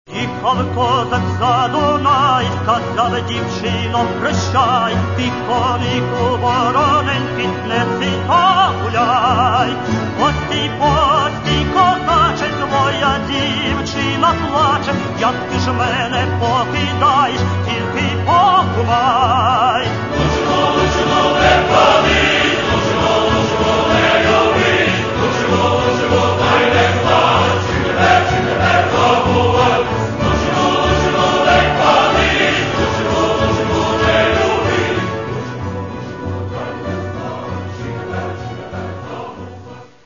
Catalogue -> Folk -> Bandura, Kobza etc
lyrics: ukrainian folk song